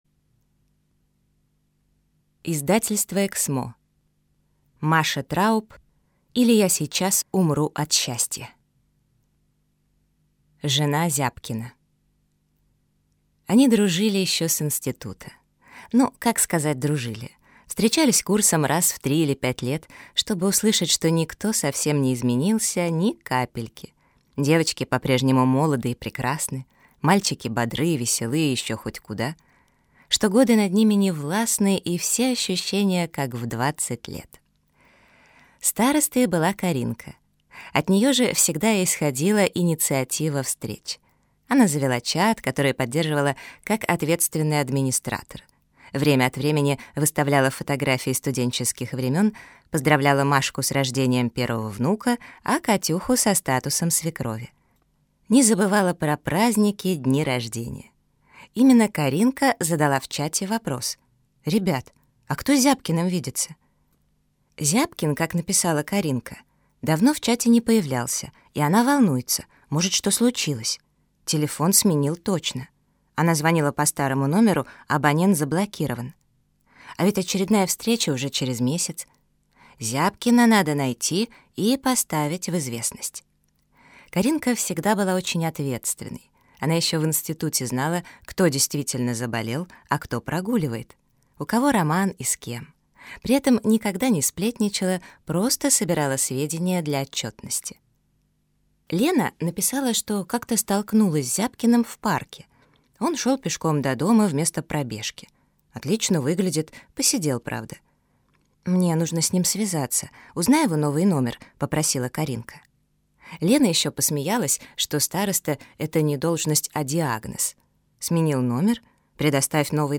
Аудиокнига Или я сейчас умру от счастья | Библиотека аудиокниг